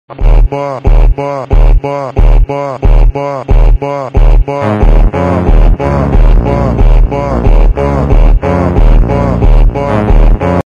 best budget keyboard oat|8k polling sound effects free download